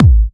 VEC3 Bassdrums Trance 60.wav